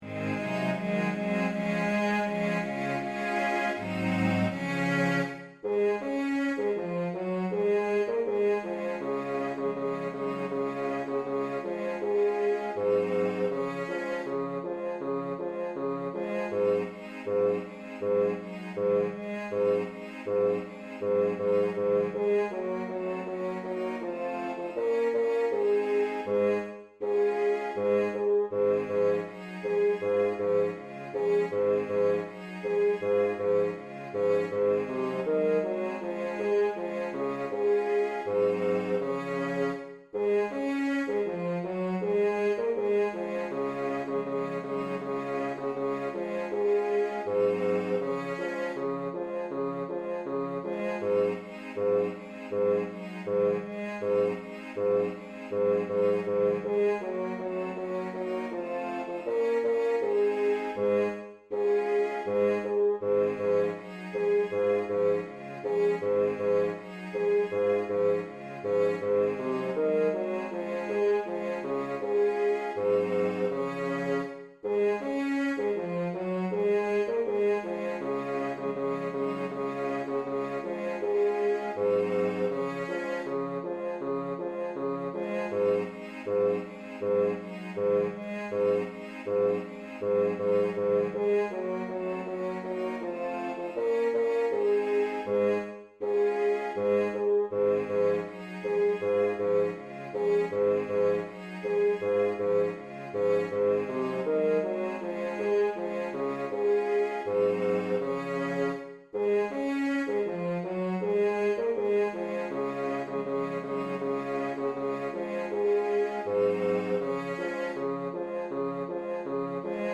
das-wandern-ist-des-muellers-lust-ttbb-zoellner-einstudierung-bass-2.mp3